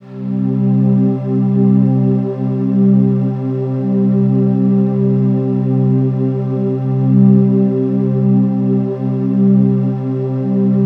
Index of /90_sSampleCDs/Infinite Sound - Ambient Atmospheres/Partition A/04-BEAUTY B